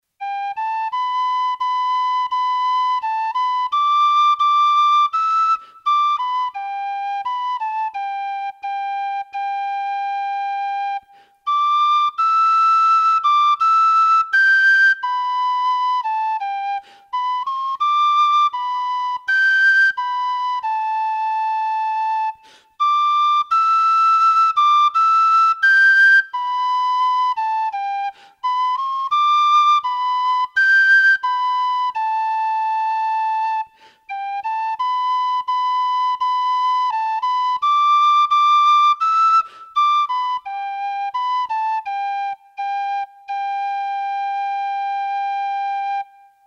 Traditional Irish Music - learning resources
Traditional Irish Music -- Learning Resources Dawning of the Day, The (March) /
Simple Audio -- Piano